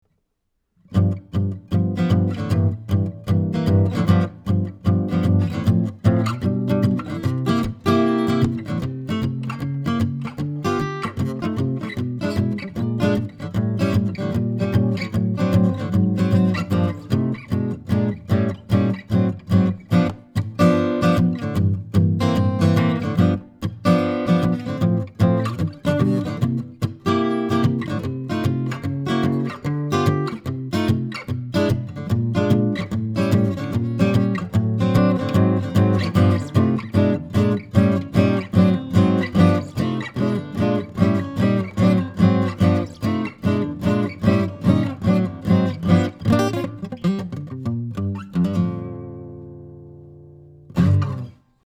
Here are some of my sound files of the BB4 equipped with an Audient Black Preamp -- just to show you the sound of the Black Series preamp, using a Blackspade Acoustics UM17 tube mic, and recorded into a Sony PCM D1 flash recorder, with no EQ or Compression: